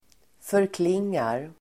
Ladda ner uttalet
Uttal: [förkl'ing:ar]